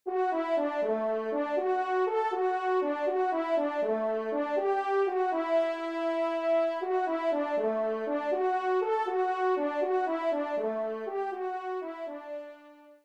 Trompe Solo (TS)